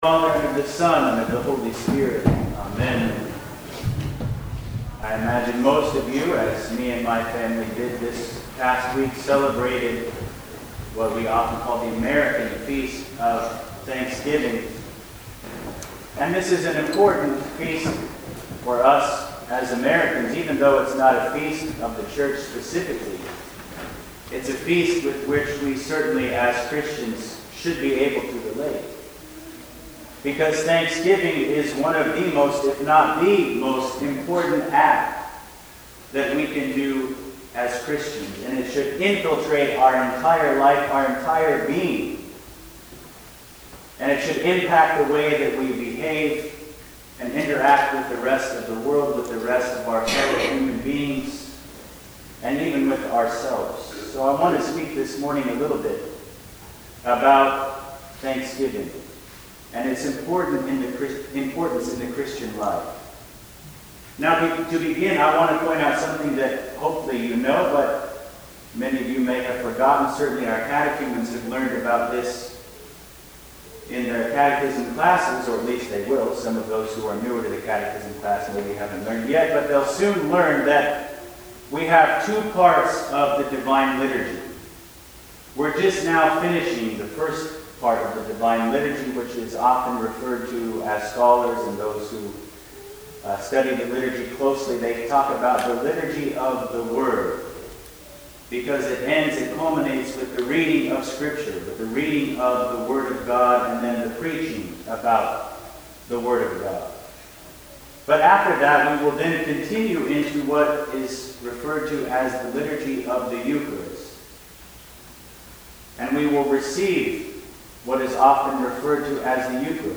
Sermons: March 12, 2023: 2nd Sunday of Lent